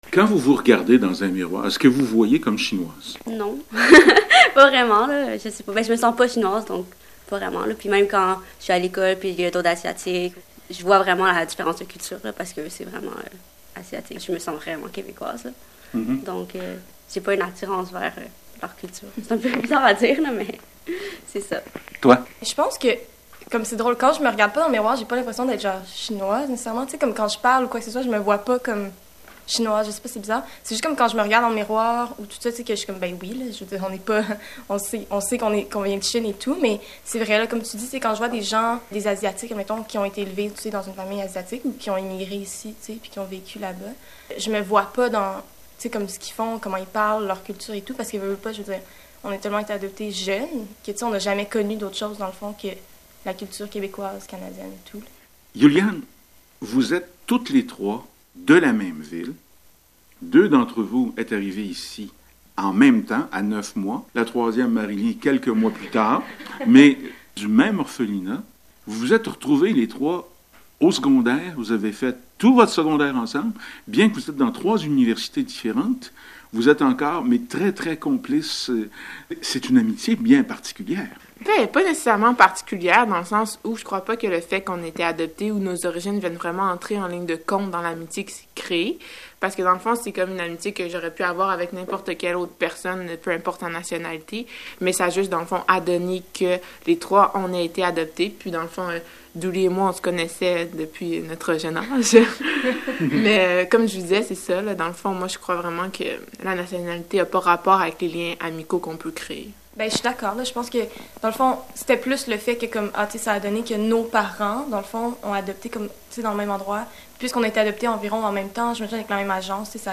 émaillée de musique, enregistrée un dimanche matin d’hiver au coin d’une table de salle à dîner